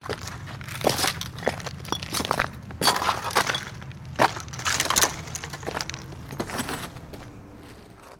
rubble_1.ogg